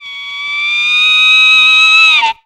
LONG SQUEAL.wav